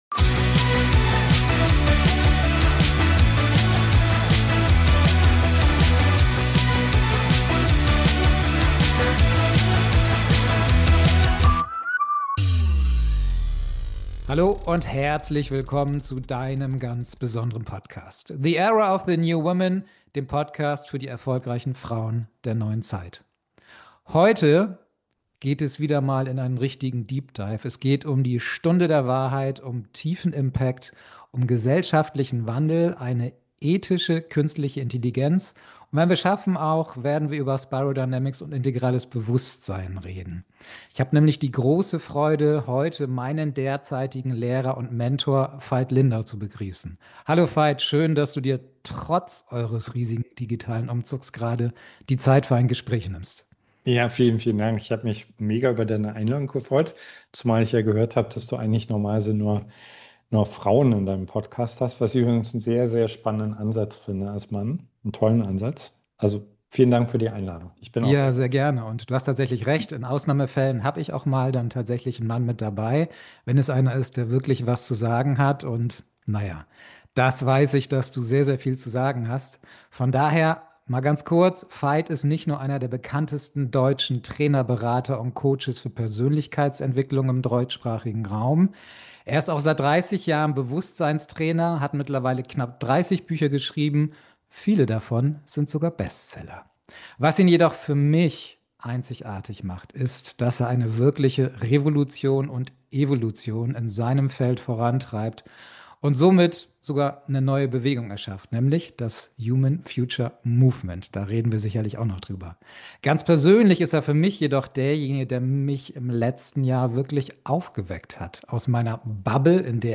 #071 Die Stunde der Wahrheit. Das INTENSIVE-Interview mit Veit Lindau. ~ The Era of the New Women Podcast